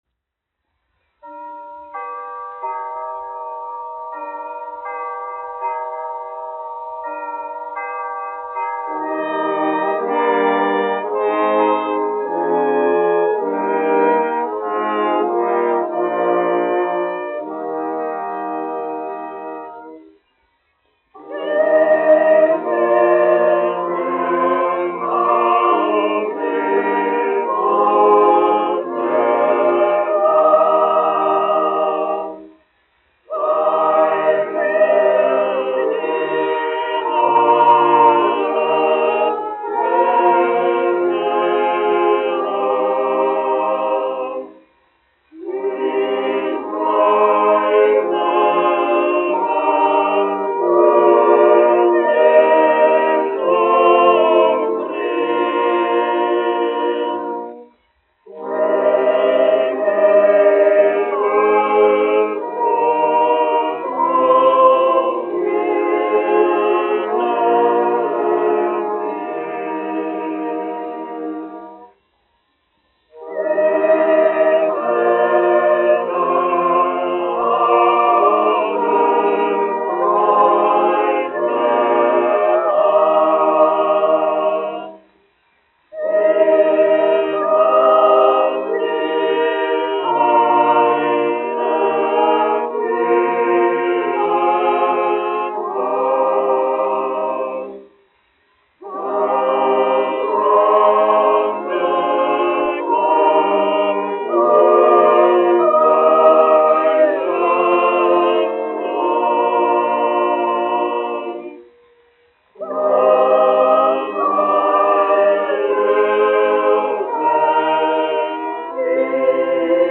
Königliche Hofoper (Berlīne, Vācija) Koris, izpildītājs
1 skpl. : analogs, 78 apgr/min, mono ; 25 cm
Ziemassvētku mūzika
Kori (jauktie)
Skaņuplate